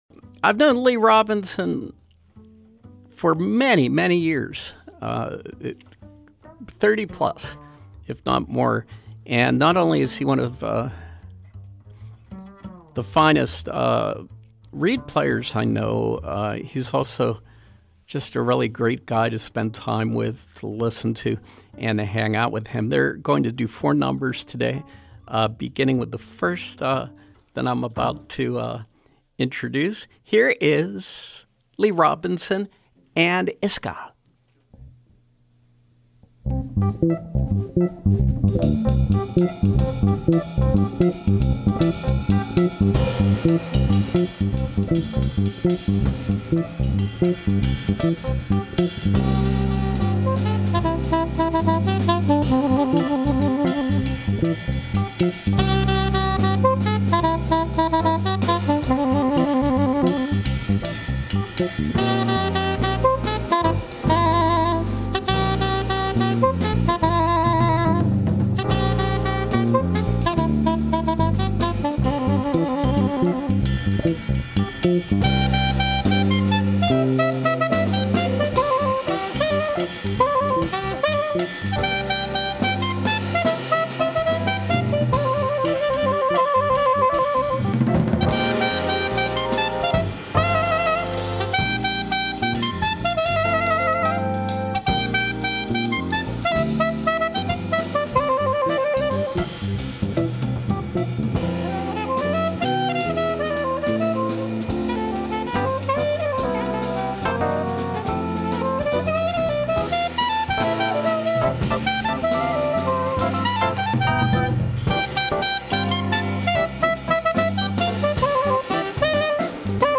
Pittsburgh Jazz musician, saxophonist
Plays with persision and soul.